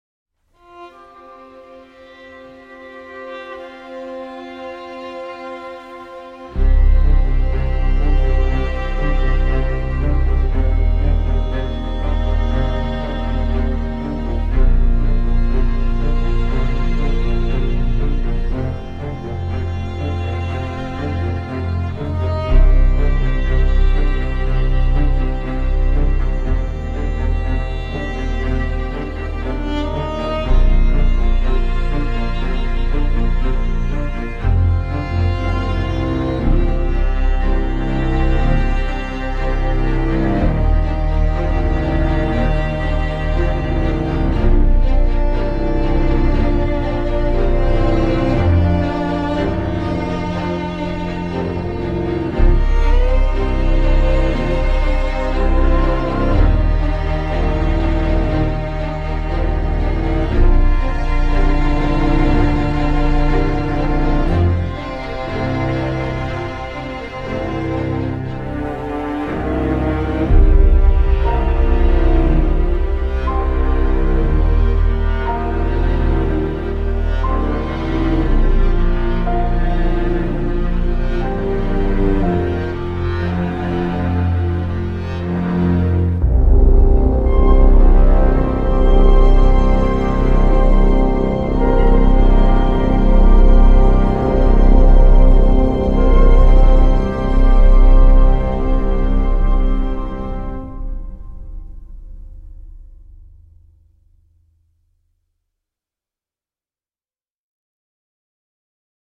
Un piano, quelques bois
son ton poignant et mélodramatique.